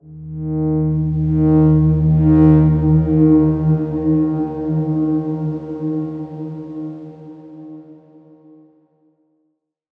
X_Darkswarm-D#2-pp.wav